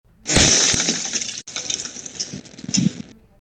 In July of 2024 a security camera of a homeowner’s backyard recorded the meteorite landing meters away from the roof of the house.
Other people guessed that the sound was a fire starting, a tree falling or maybe a tree branch breaking and even just ice shattering.
Another Londoner even compared the sound to a start of a kindling fire and another mixed sound.
meteoriterawsound.mp3